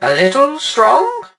barley_kill_02.ogg